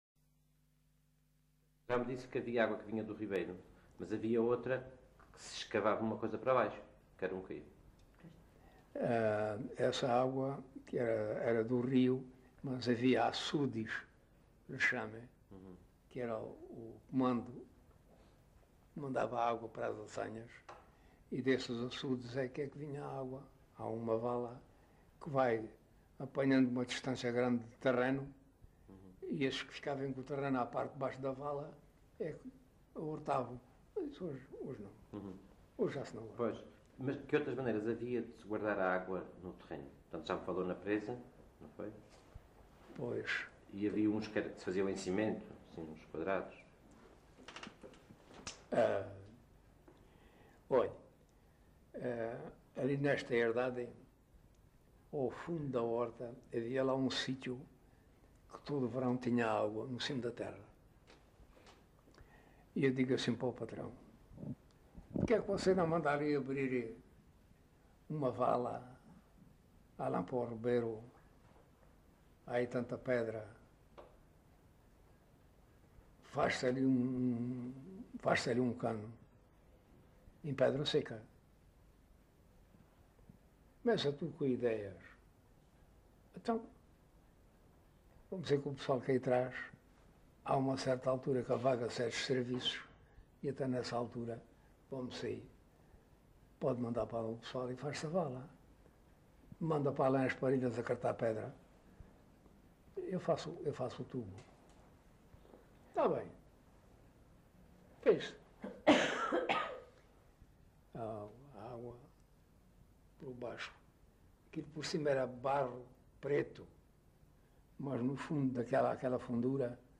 LocalidadeCabeço de Vide (Fronteira, Portalegre)